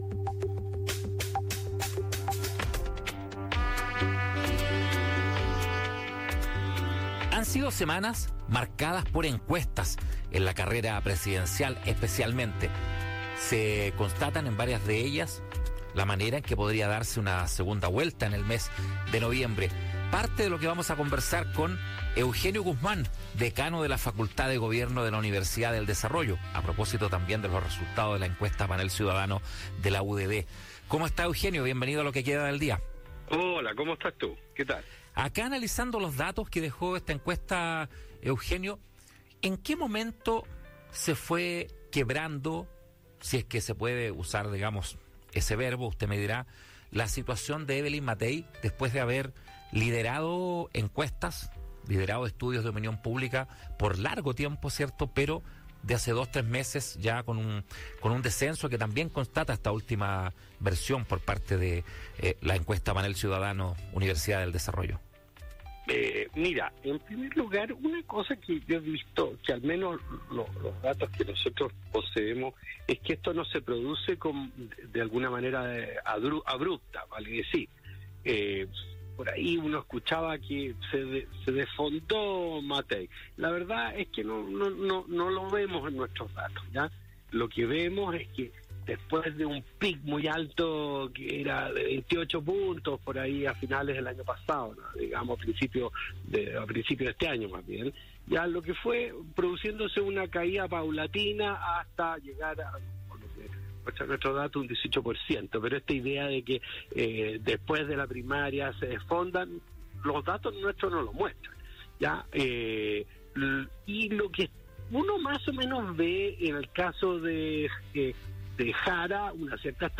El académico comentó en Lo Que Queda del Día los resultados de la más reciente encuesta Panel Ciudadano-UDD , que dio a Jeannette Jara un 26 por ciento de intención de voto, seguida por José Antonio Kast (24 por ciento) y Evelyn Matthei (18 por ciento).